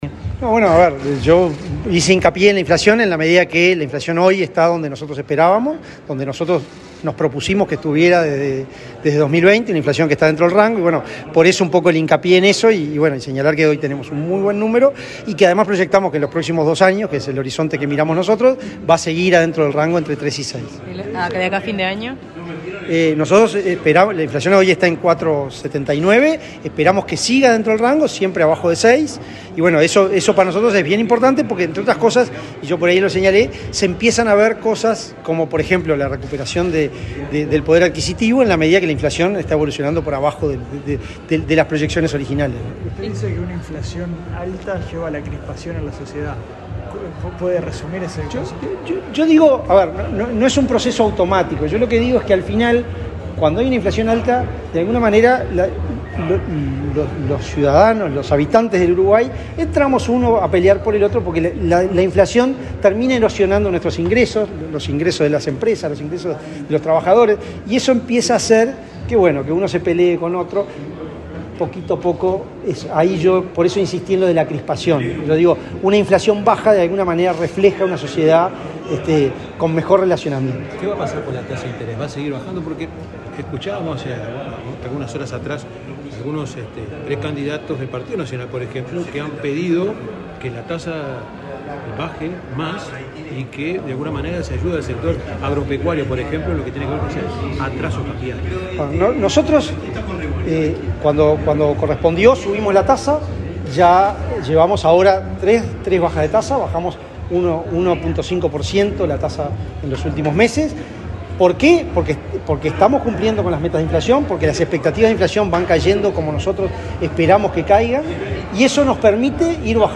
Declaraciones del presidente del BCU, Diego Labat
Luego, dialogó con la prensa.